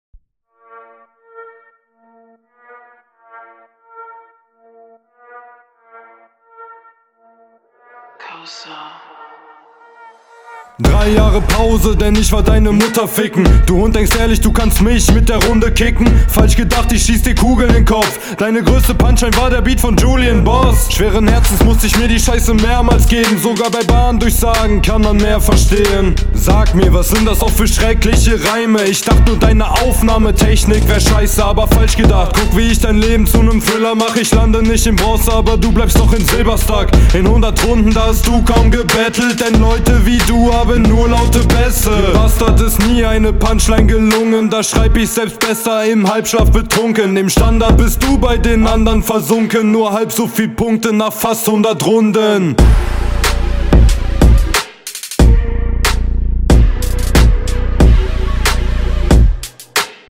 Flow cool, Mix gut, Punches hart, Reime stabil